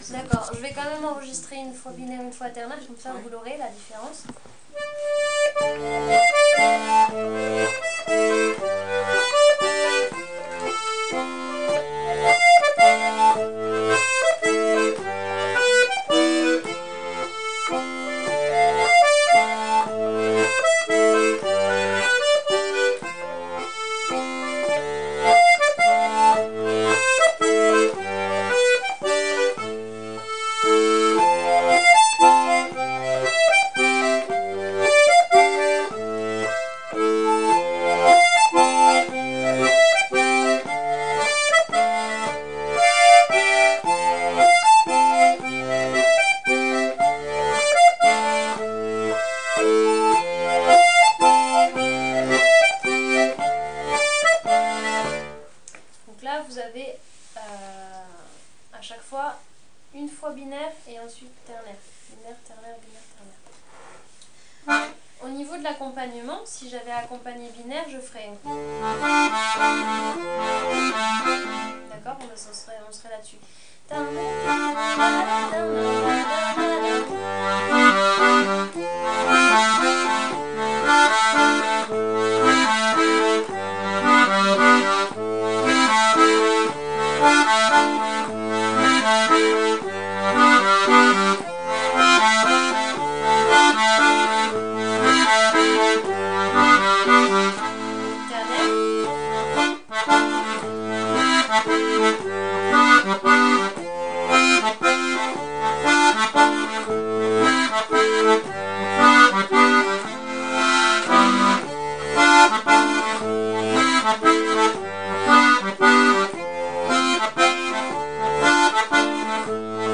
l'atelier d'accordéon diatonique
La caractéristique de la mazurka est le rythme ternaire: exemple de jeu binaire et ternaire
binaire et ternaire sur la mélodie et puis sur l'accompagnement